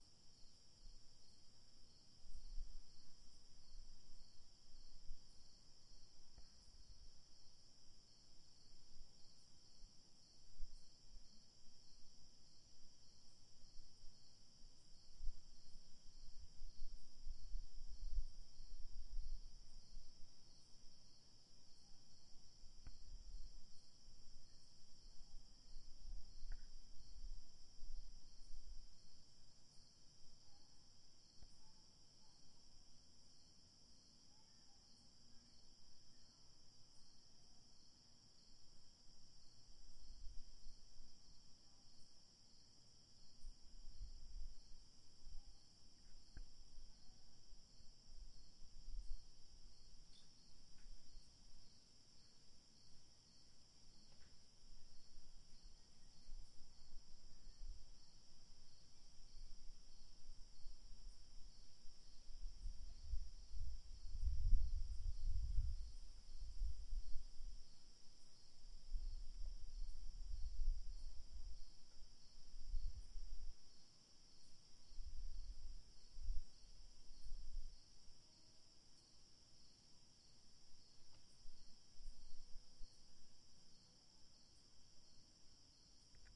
描述：tascam录制的夜晚在安静的社区里的室外，鸣叫，静止的天气，夏末，二楼阳台上的街道
Tag: 鸣叫 门廊